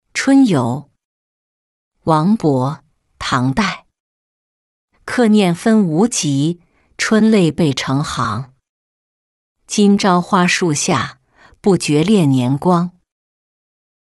春游-音频朗读